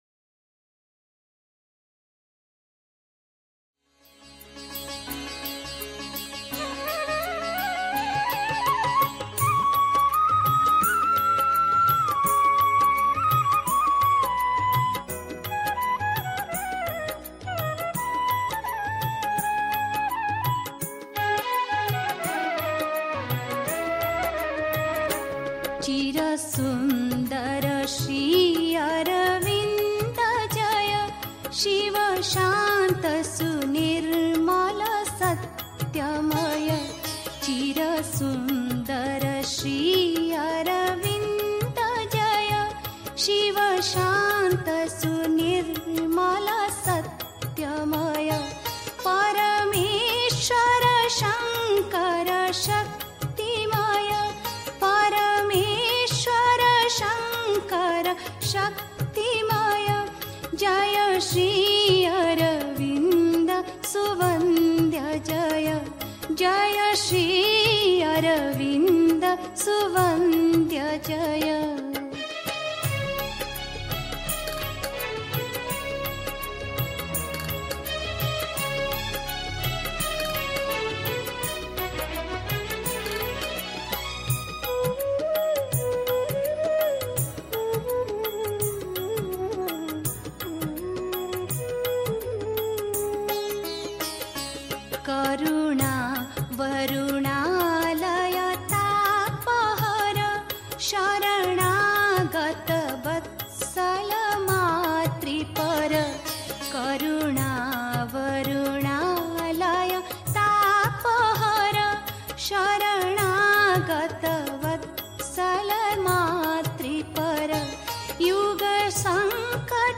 1. Einstimmung mit Musik. 2. Die Haltung, in die man hineinwachsen muss (Sri Aurobindo, SABCL, Vol. 23, p. 587) 3. Zwölf Minuten Stille.